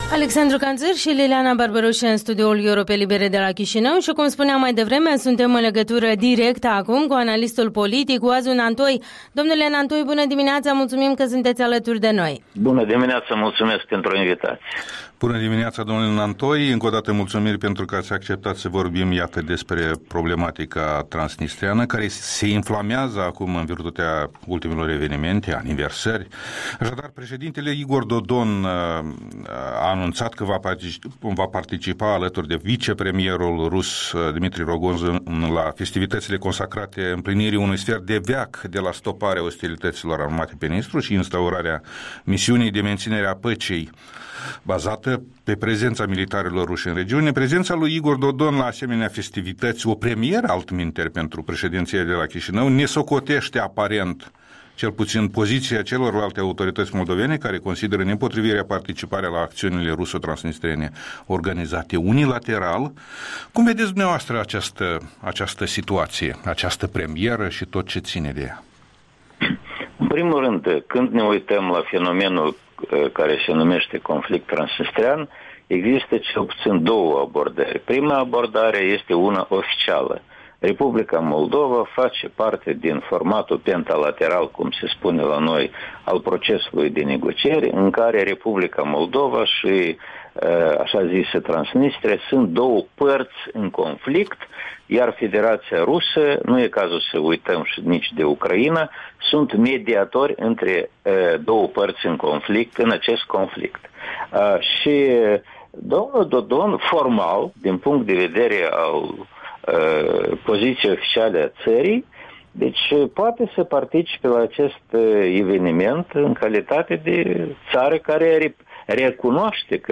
Interviul dimineții cu un expert politic de la Chișinău, despre aniversarea a 25 de ani de la declanșarea operațiunii de menținere a păcii pe Nistru.
Interviul dimineții: cu expertul politic Oazu Nantoi